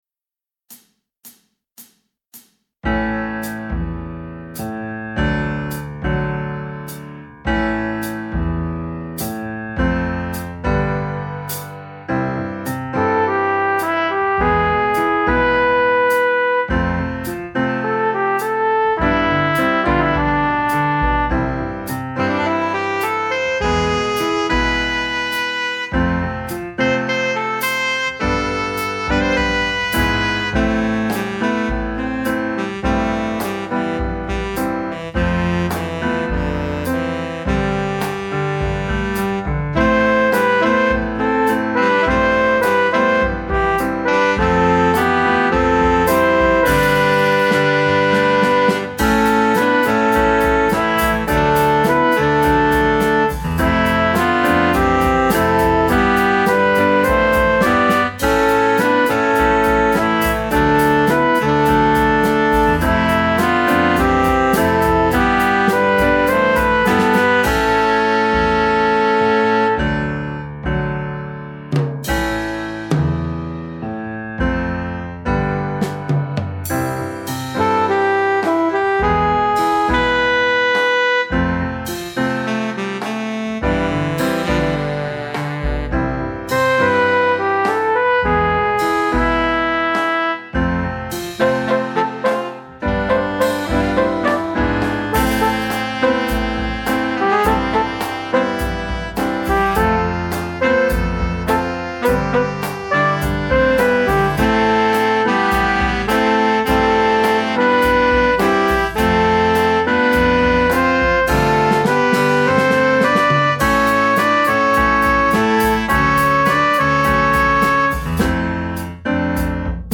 minus Bass